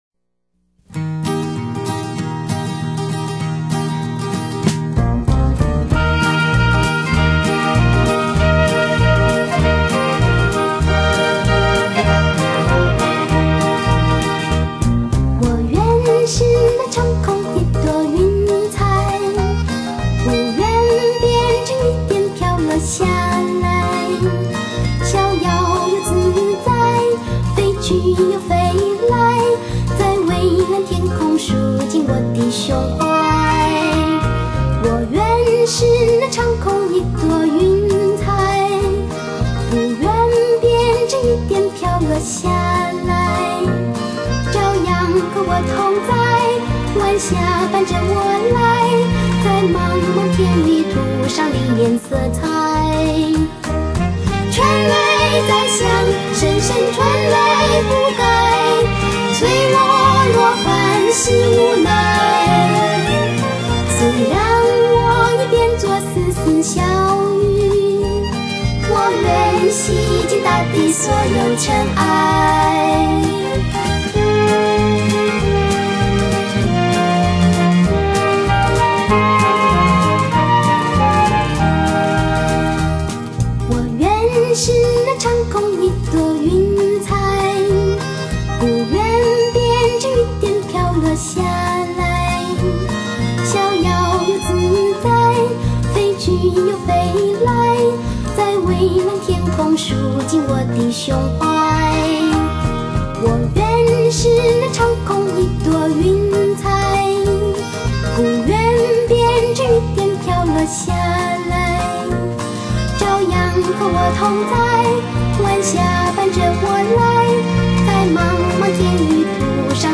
正宗絕對原聲原唱!
校園民歌運動已滿30週年，在您記憶的行囊中，還遺留有多少的青春音韻？